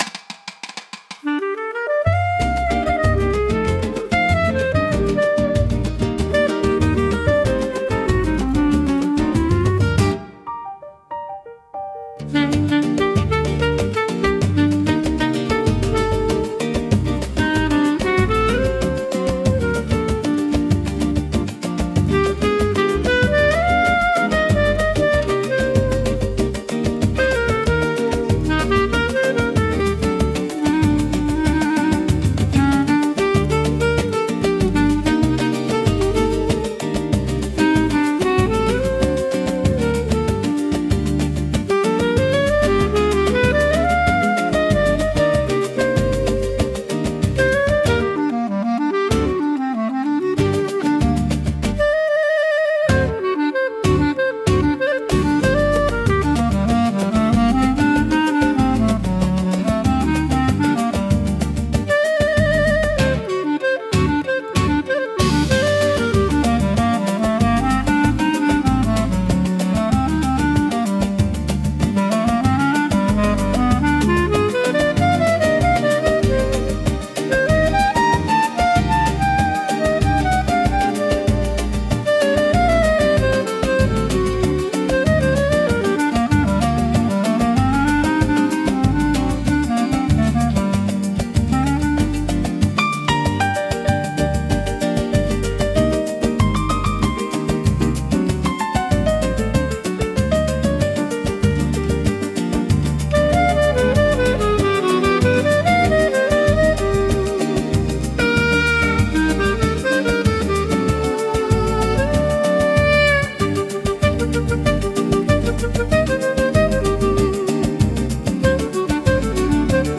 música e arranjo: IA) instrumental 5